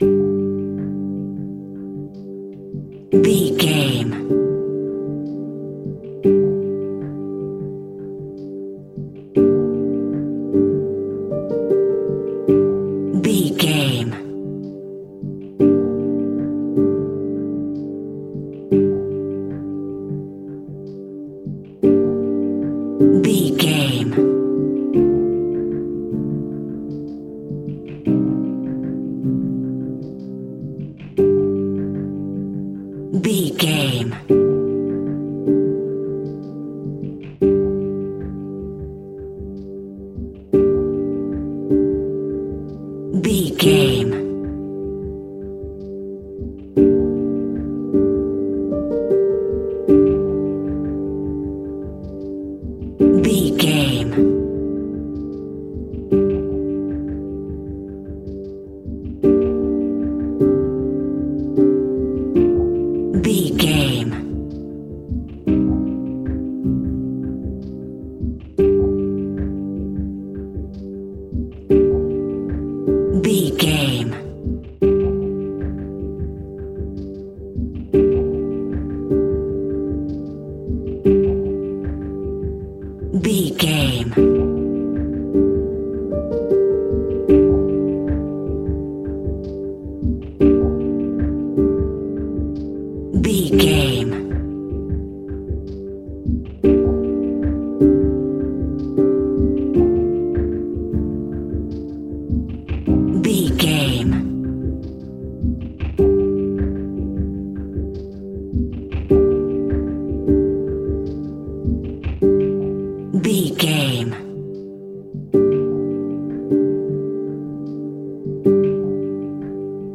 Ionian/Major
suspense
synthesiser